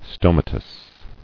[sto·ma·tous]